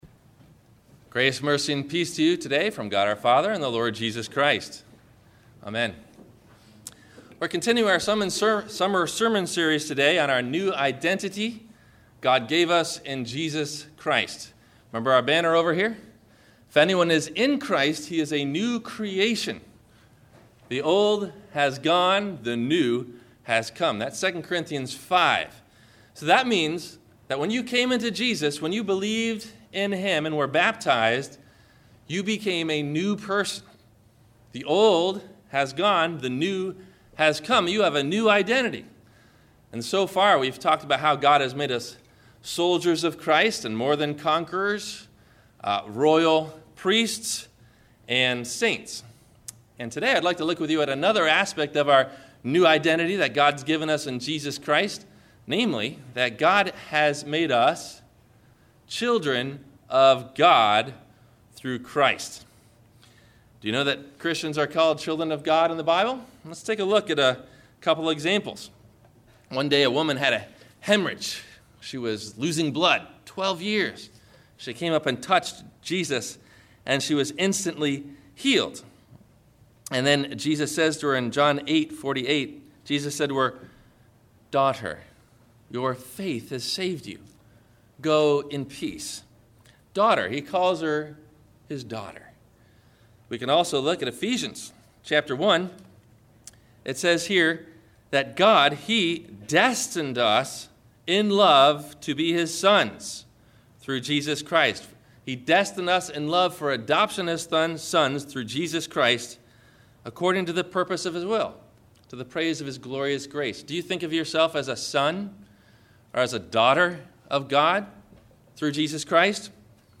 Children of Wrath or Children of God ? – Sermon – July 08 2012